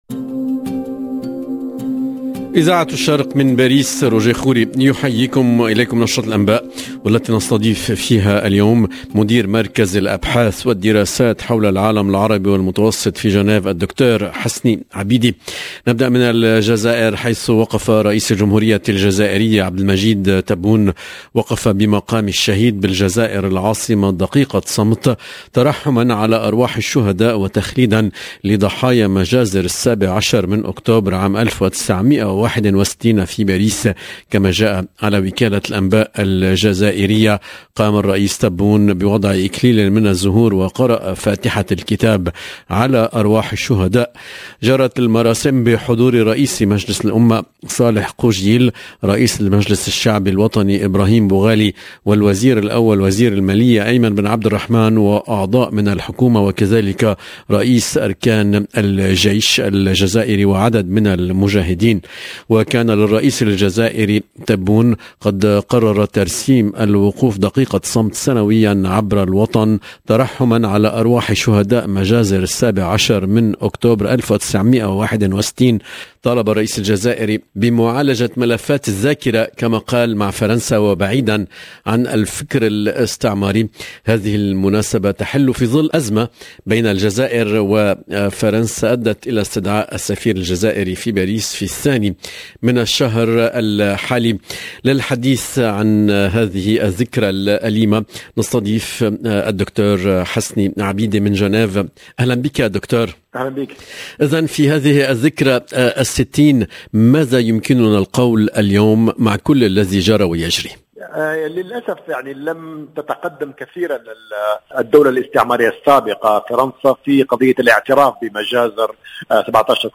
LE JOURNAL DU SOIR EN LANGUE ARABE DU 17/10/21